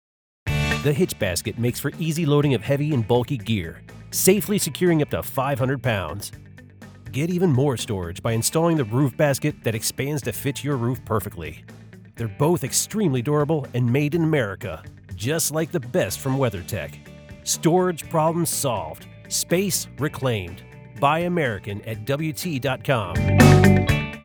Masculine automotive accessory spot
• Environment: Fully treated, certified-professional home studio for noise-free, clean narration